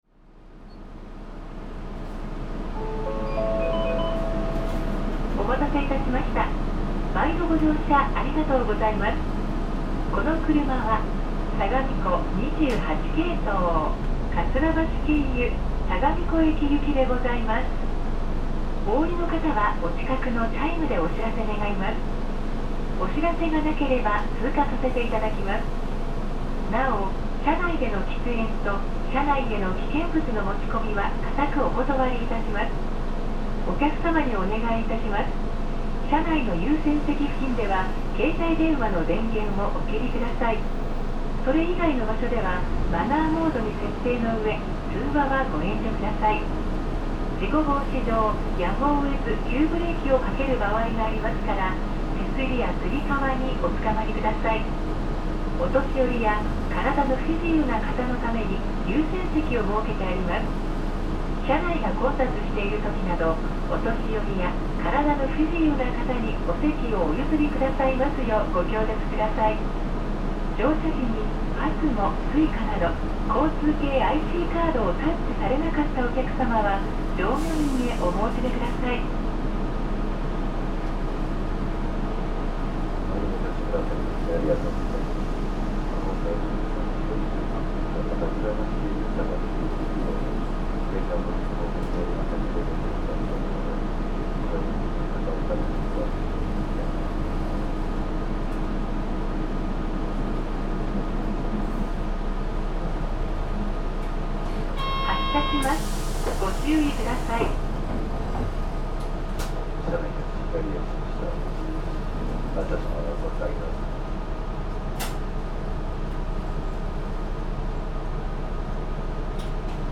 神奈川中央交通 三菱ふそう KL-MP35JM ・ 走行音(ターボ付・全区間) (28.6MB*) 収録区間：港40系統 栄プール前→港南台駅 多数が導入されたKL-MPワンステップ車で、各営業所に在籍している。